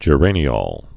(jə-rānē-ôl, -ōl, -ŏl)